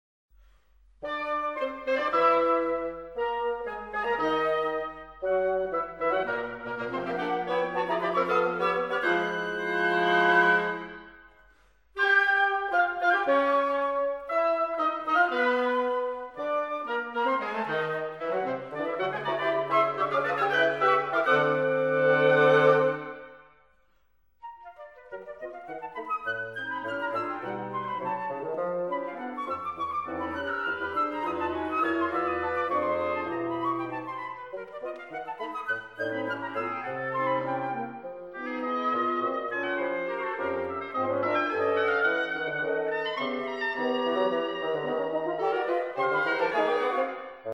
Besinnliche und virtuose Musik